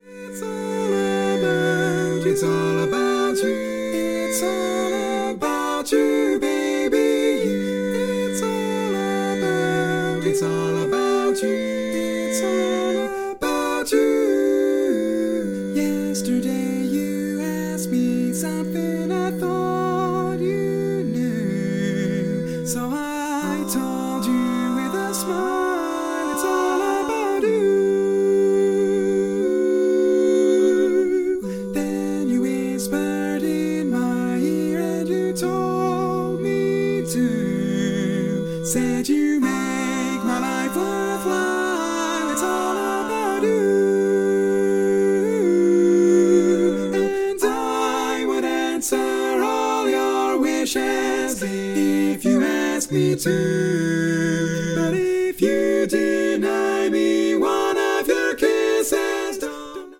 Category: Female